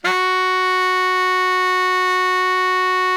BARI  FF F#3.wav